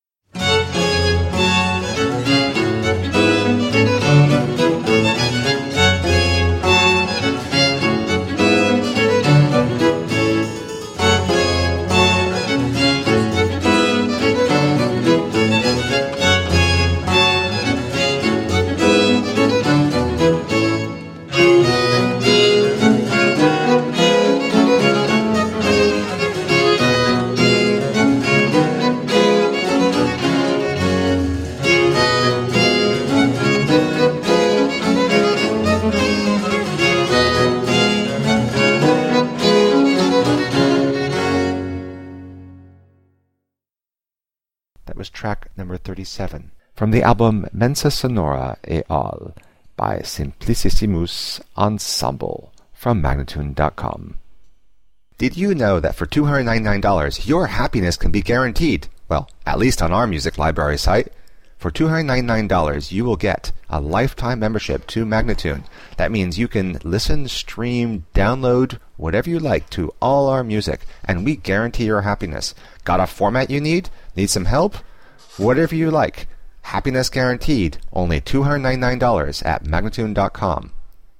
17th and 18th century classical music on period instruments
Classical, Instrumental Classical, Classical Period, Baroque